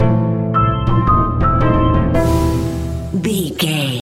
Aeolian/Minor
scary
ominous
eerie
piano
strings
organ
flute
percussion
spooky
horror music